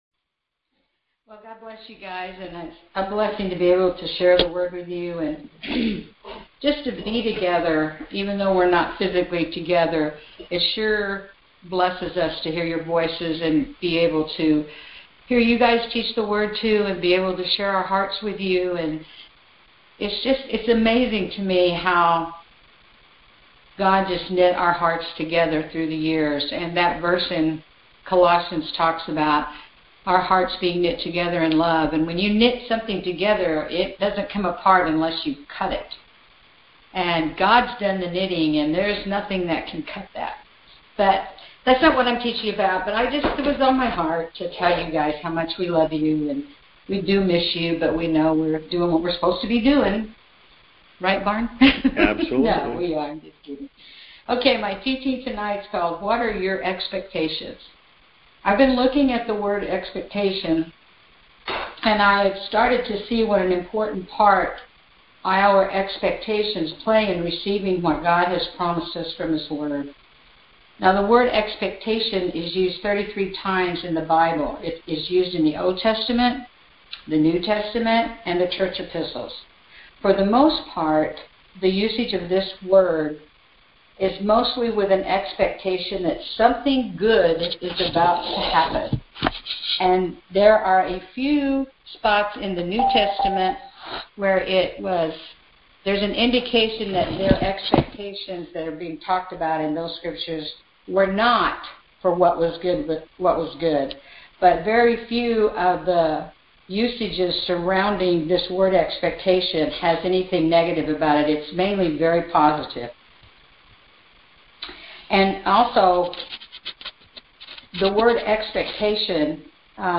Conference Call Fellowship Date